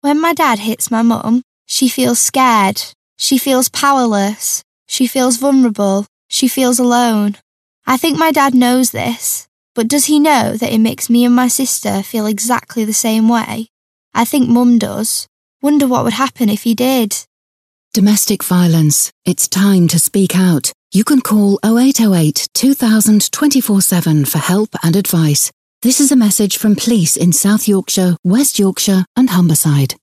"Be Safe this Christmas" Campaign - Domestic Violence Radio Advert